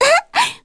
Ophelia-vox-Laugh_2_kr.wav